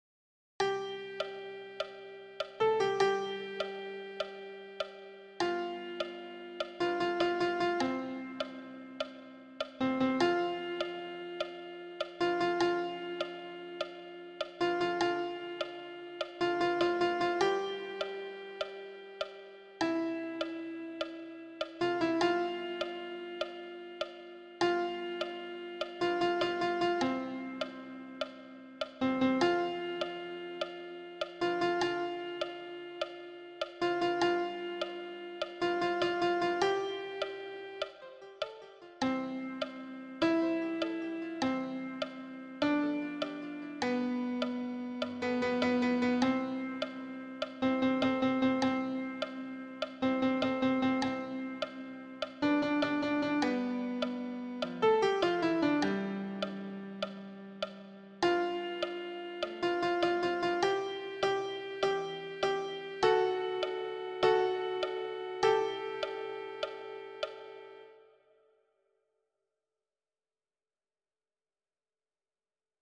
Répétition SATB par voix
Alto
Non, je ne regrette rien_alto-mix.mp3